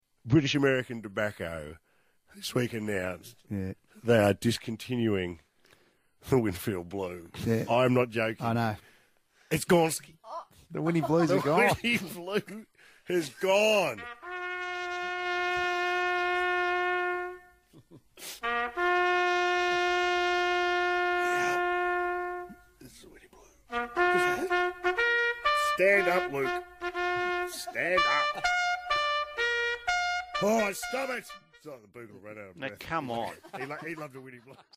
From Triple M: Mick Molloy plays The Last Post during joke about cigarettes